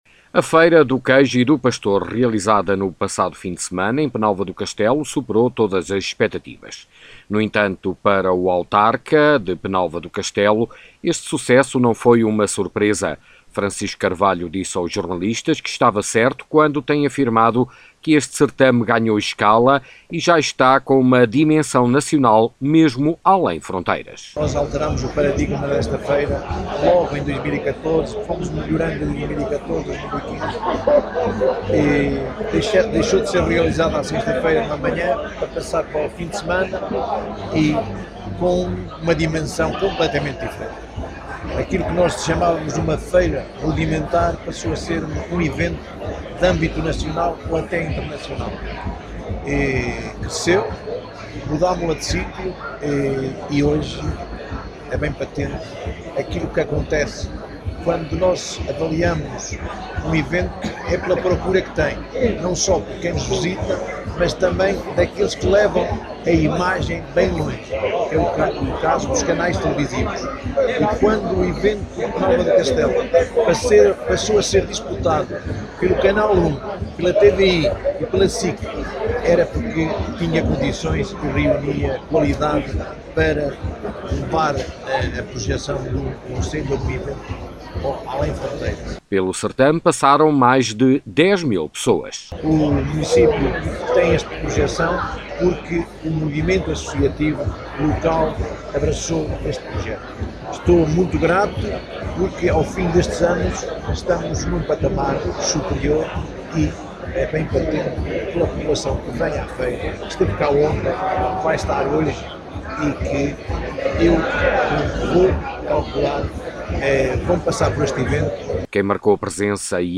Francisco Carvalho – Presidente da Camâra Municipal de Penalva do Castelo
Pedro Machado – Secretário de Estado do Turismo